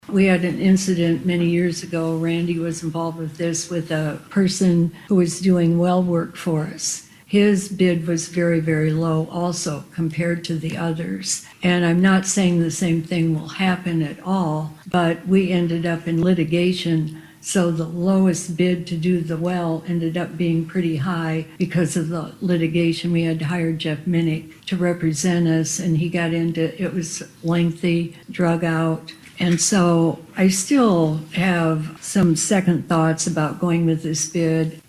She cited a previous example of the council selecting the lowest bidder.